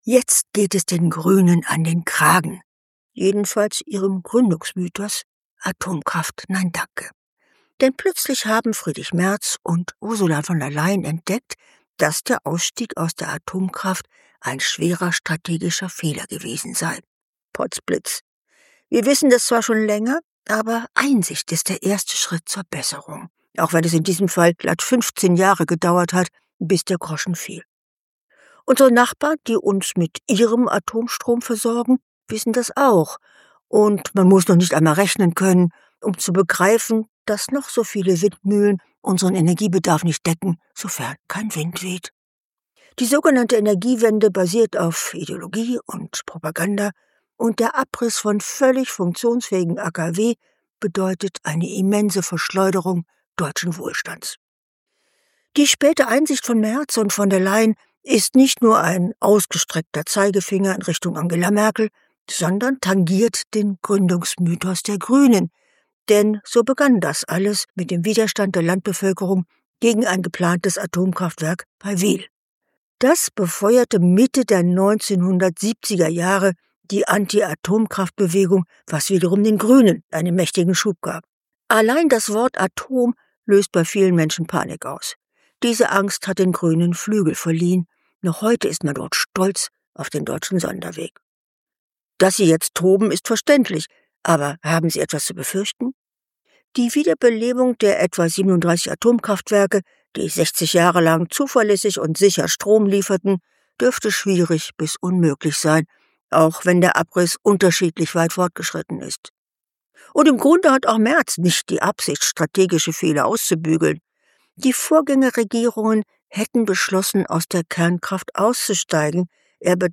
Cora Stephan – Kontrafunk Kommentar 13.3.2026